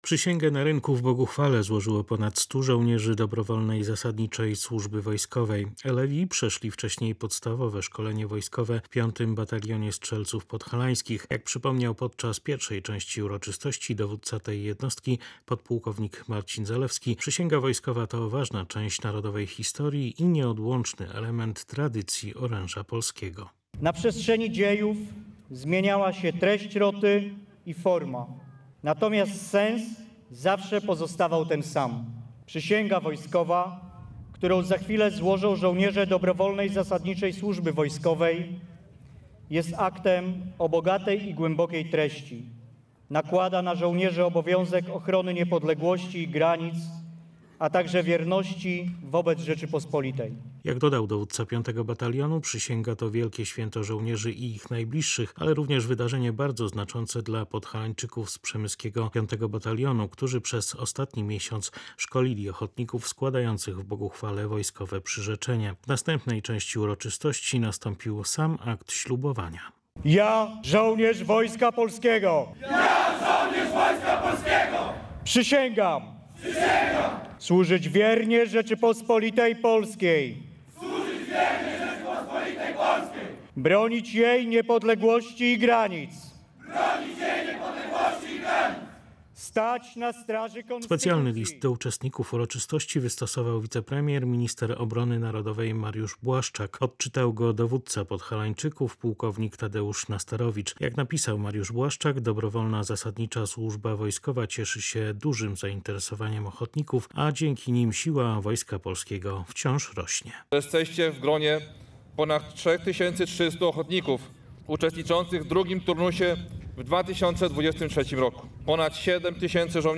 Przysięgę wojskową złożyło w sobotę na Rynku w Boguchwale ponad stu żołnierzy dobrowolnej zasadniczej służby wojskowej.
Wojewoda podkarpacki Ewa Leniart, gratulując elewom w imieniu rządu podjęcia decyzji o służbie wojskowej przypominała, że żyjemy w czasach pełnych niepokoju i dlatego chcemy, aby Rzeczpospolita była bezpieczna.